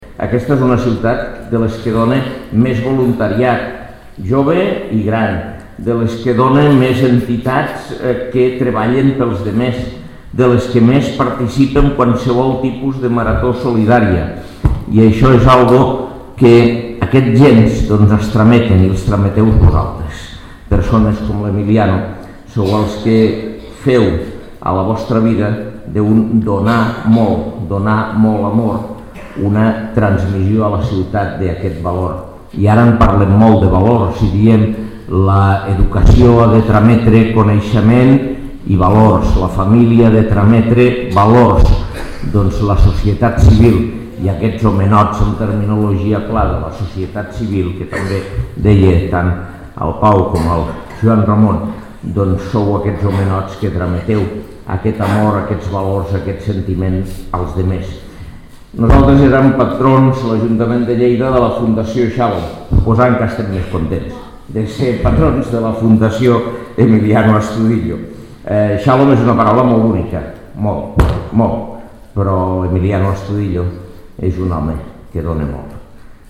Tall de veu de l'alcalde, Àngel Ros